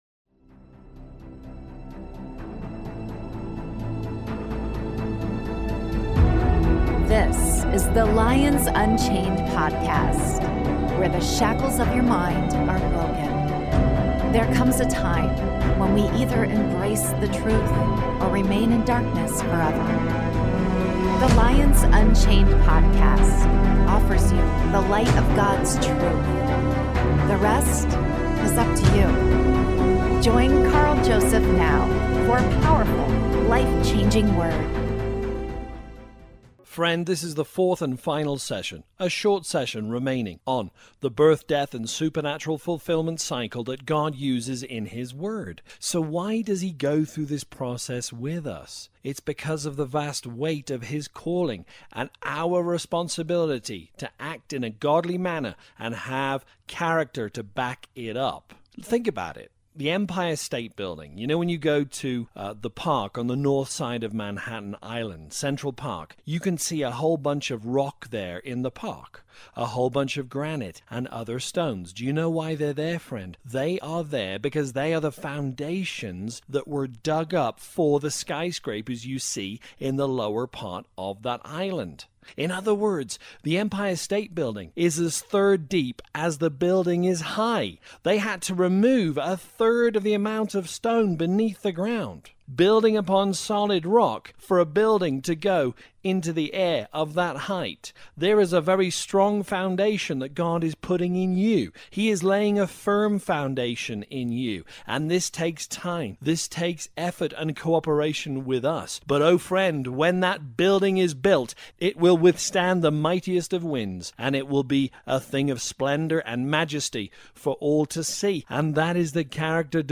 The BDSF Cycle: Part 4 (LIVE)